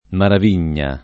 Maravigna [ marav & n’n’a ] cogn.